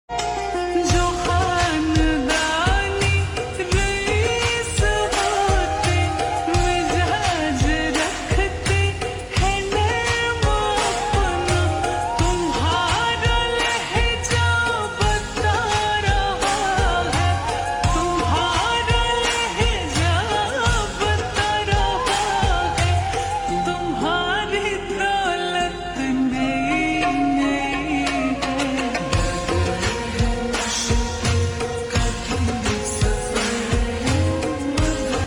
• Simple and Lofi sound
• Crisp and clear sound